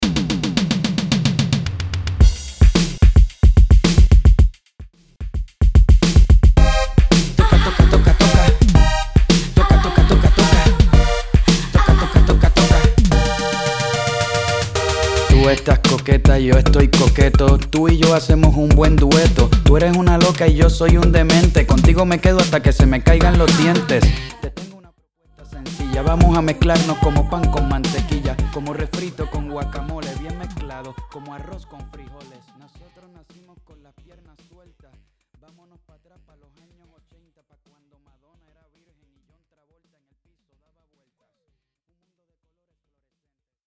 Latin music remixes